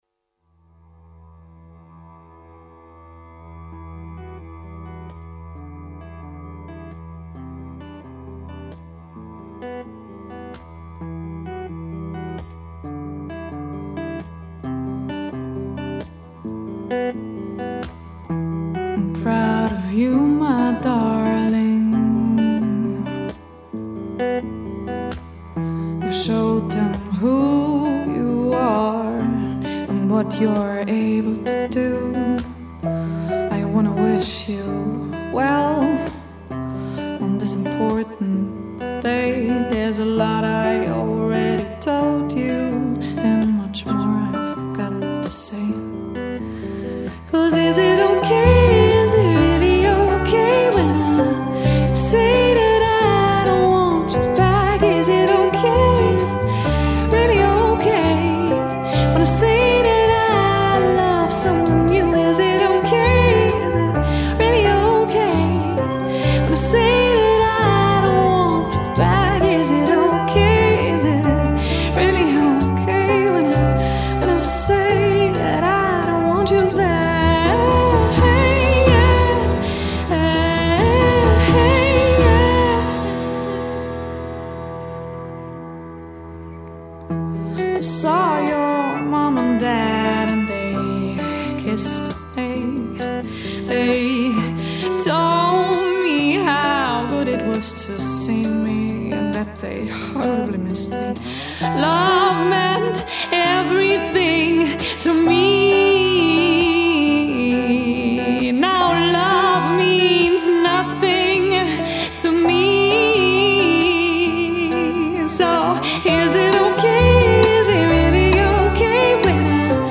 Folk-rock / singer-songwriter.